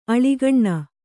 ♪ aḷigaṇṇa